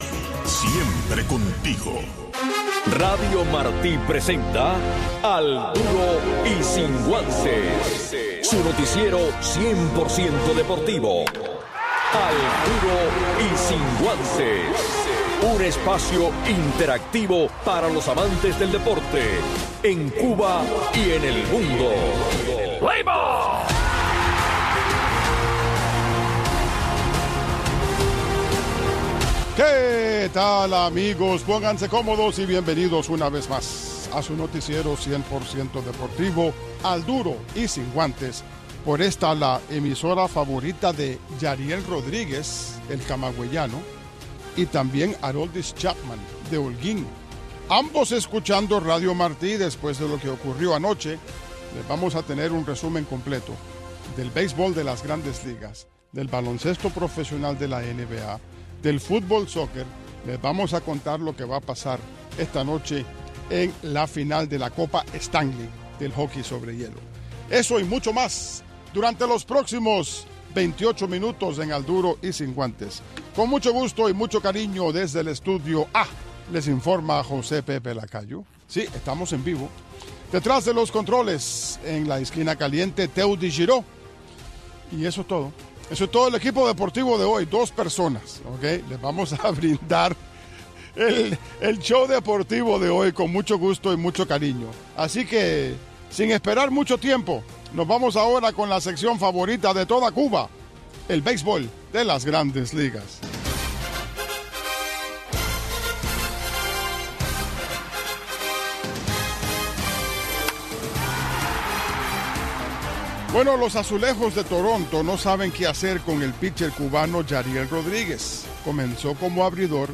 Un resumen deportivo en 60 minutos